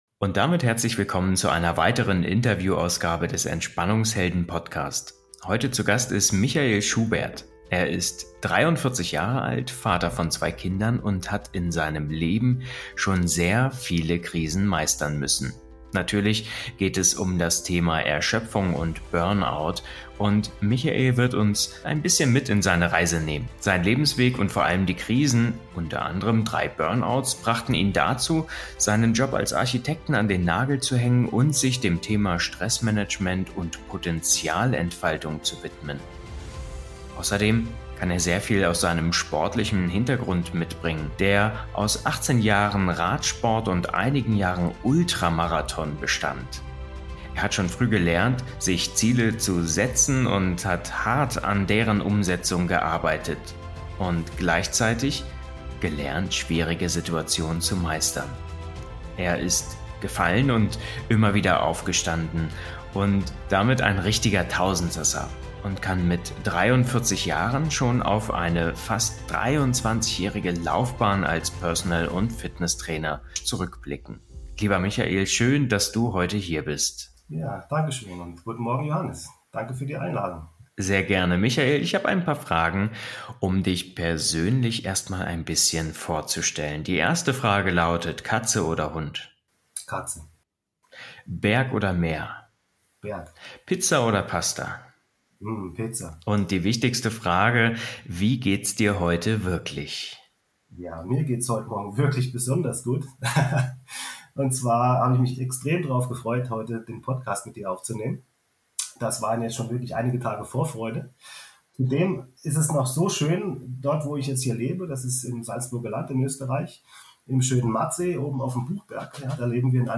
Burnout als Leistungsstatus? Gespräch